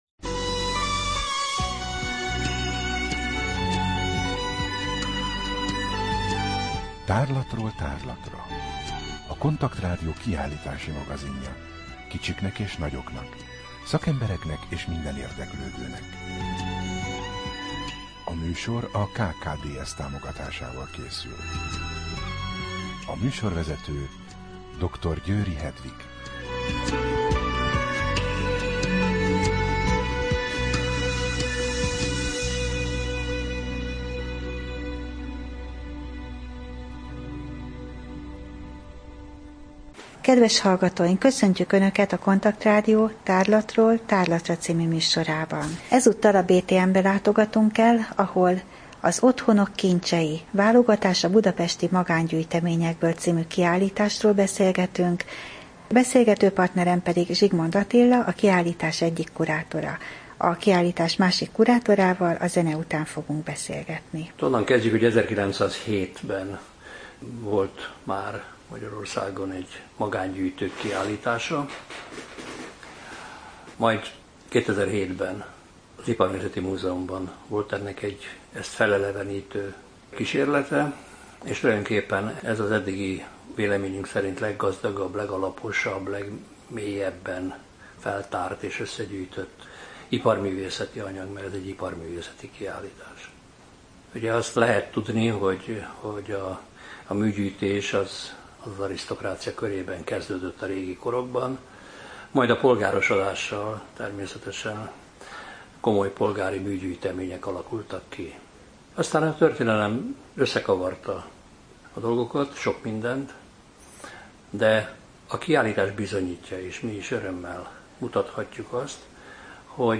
Rádió: Tárlatról tárlatra Adás dátuma: 2014, Február 28 Tárlatról tárlatra / KONTAKT Rádió (87,6 MHz) 2014 február 24. A műsor felépítése: I. Kaleidoszkóp / kiállítási hírek II. Bemutatjuk / Otthonok kincsei, BTM Vármúzeum A műsor vendége